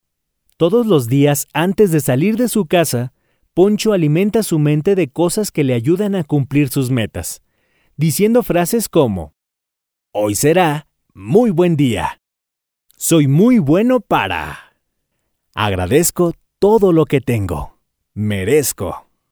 Gracias al tono que manejo (voz media), puedo ofrecer diferentes estilos y tonos para darle calidad a tu proyecto.
Sprechprobe: Industrie (Muttersprache):